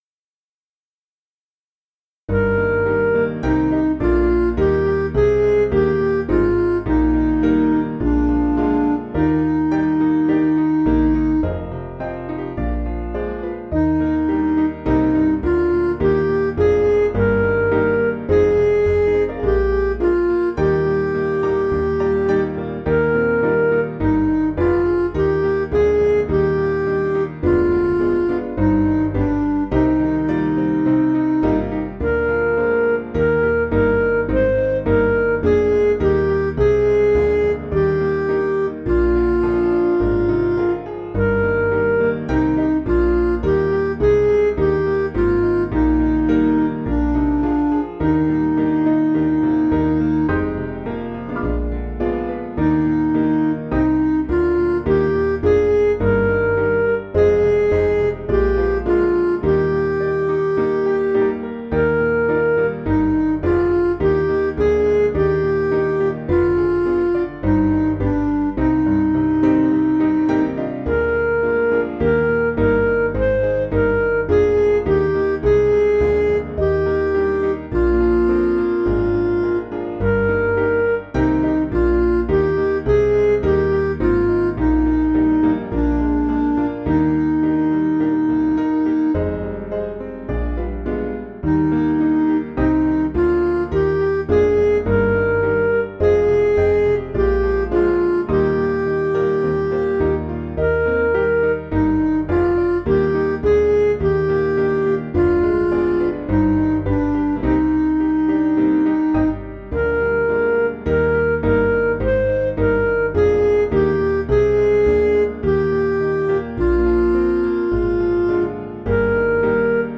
Piano & Instrumental
(CM)   4/Eb